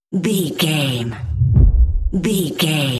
Dramatic hit very deep trailer
Sound Effects
Atonal
heavy
intense
dark
aggressive